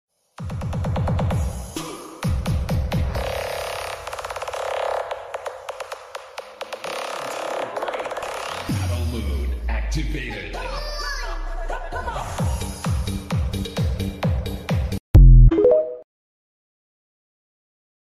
nhạc 8d hãy đeo tai sound effects free download